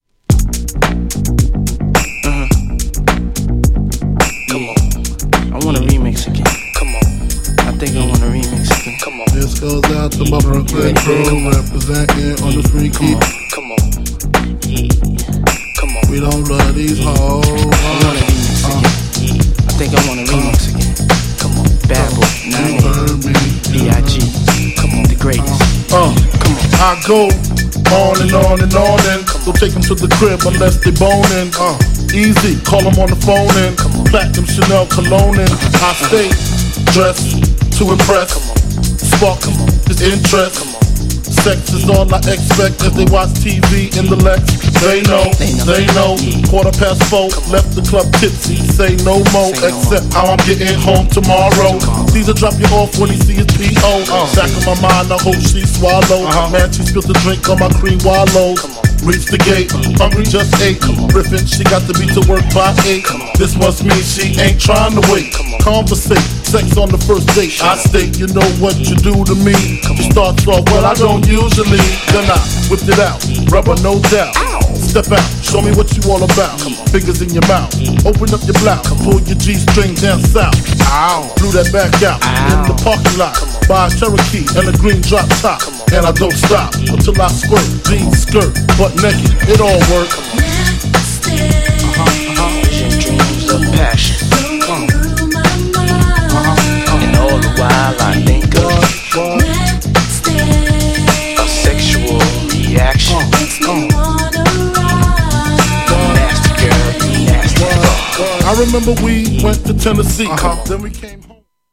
GENRE Hip Hop
BPM 101〜105BPM